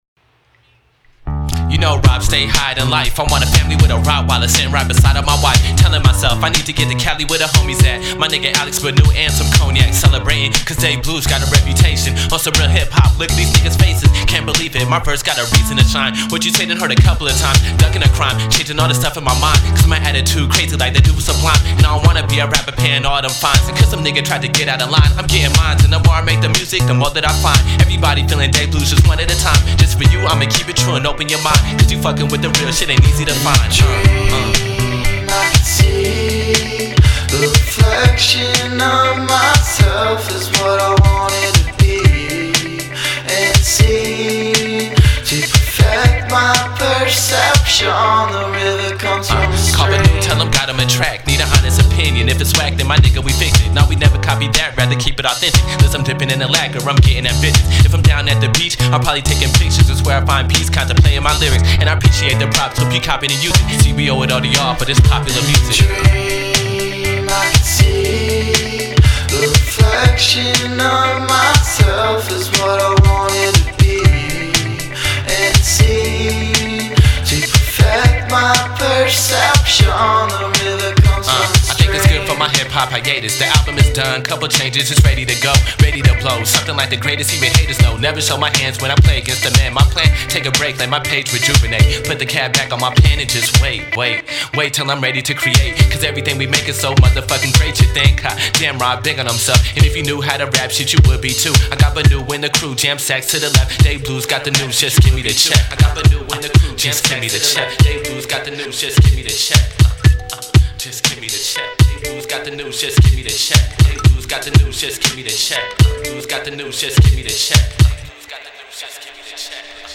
Hip Hop
Their music is very jazzy and their style is varied.